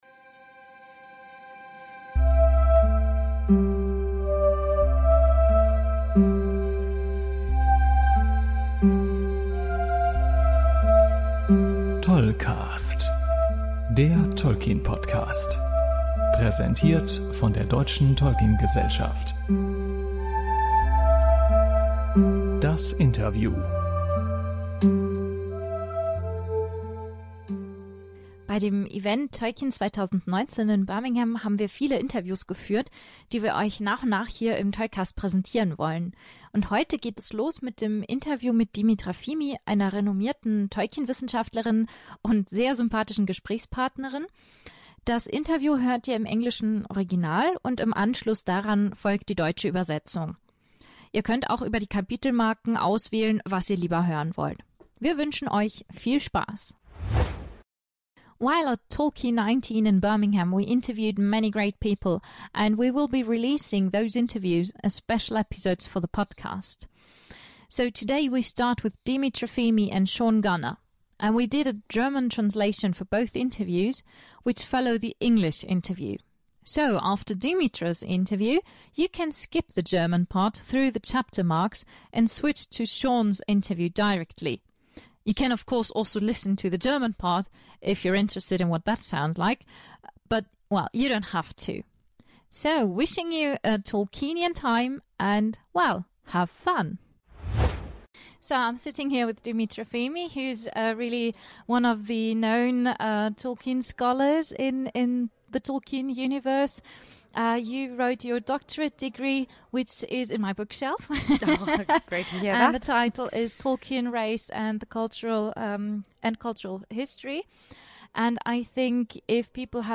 Hierbei handelt es sich um eine Sonderfolge mit nicht einem, sondern zwei Interviews aus Birmingham: Tolkien Forscherin